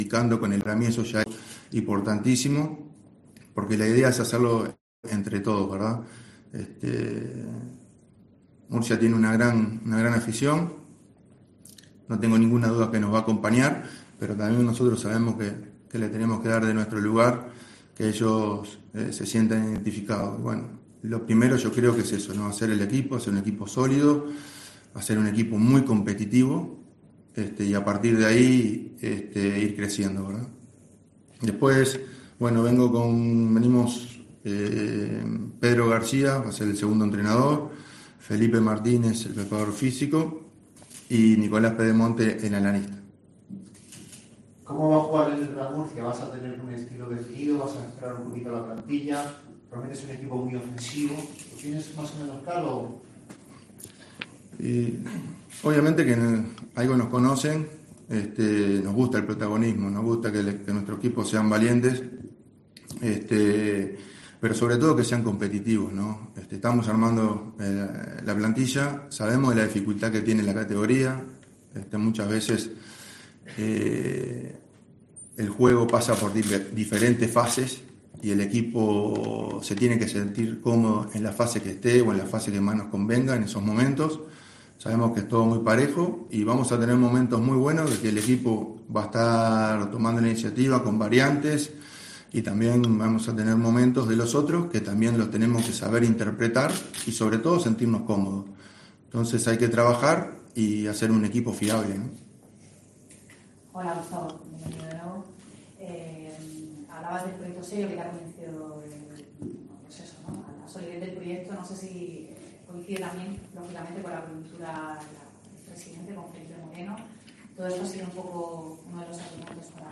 PRESENTACIÓN
El técnico charrúa, de 45 años cumplidos el 27 de enero, fue presentado este lunes en el estadio Enrique Roca como nuevo entrenador de un equipo que competirá por segundo año consecutivo en el grupo 2 de la Primera RFEF.